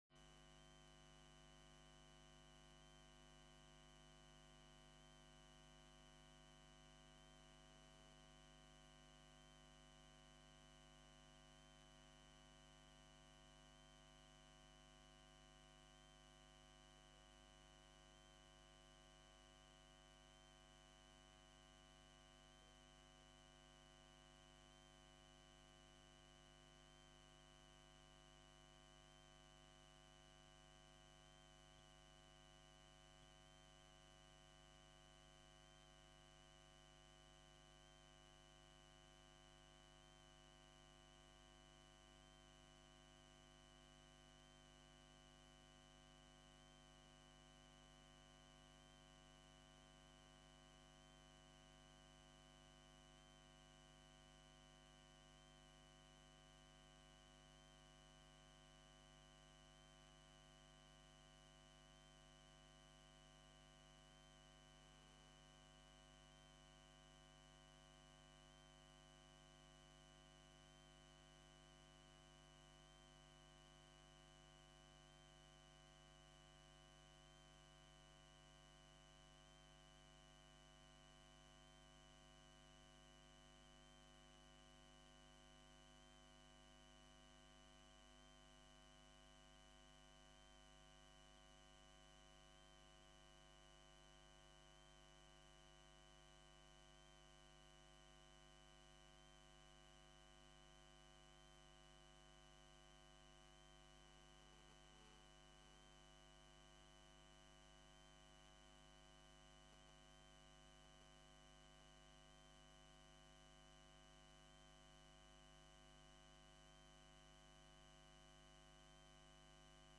Gemeenteraad 05 november 2012 19:30:00, Gemeente Den Helder
Download de volledige audio van deze vergadering